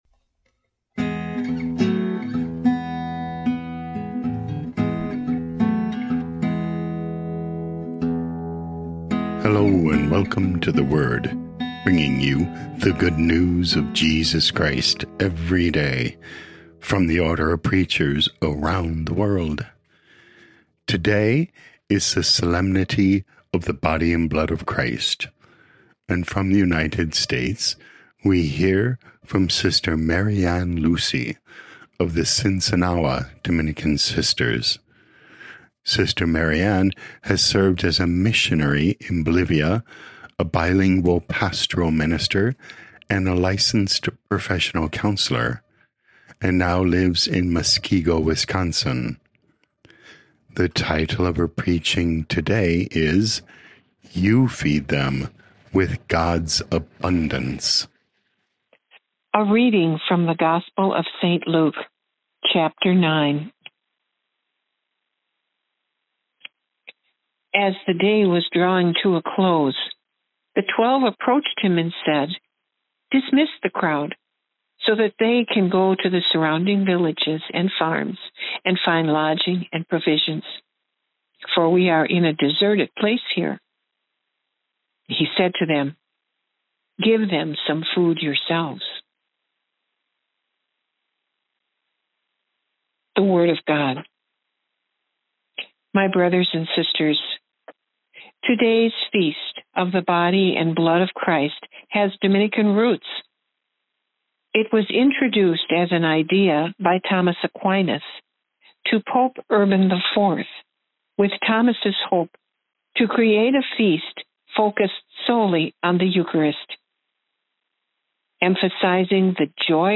OP Preaching